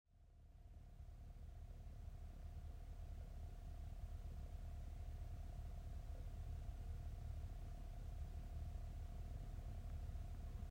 BeQuiet DARK POWER 12 850W ATX 80PLUS® Titanium Lüfter Defekt ? (Audio Aufnahme)
1. Netzteil